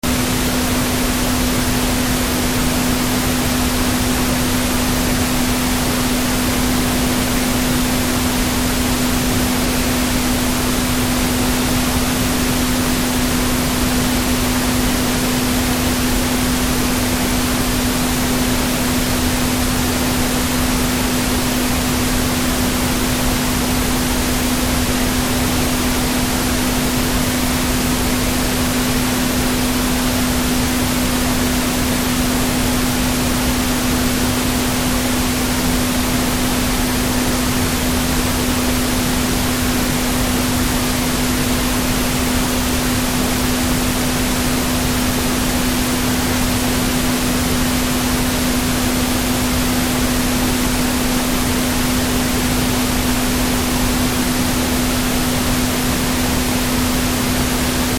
Estos sonidos tienen un mismo LAeq.
Se muestra un ejemplo para cada uno de los casos de tono puro considerado en la ordenanza, uno para el intervalo entre 25 y 125 Hz, otro para el intervalo entre 160 y 400, y otro para frecuencias mayores de 500 Hz.